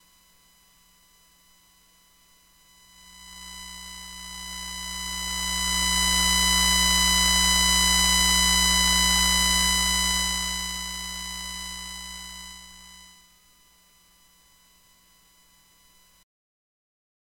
Newbie with noise issue
Roland FP7 keyboard. Behringer 802 mixer.
Mixer main volume at 0, no noise. Noise gets louder as mixer volume is turned up.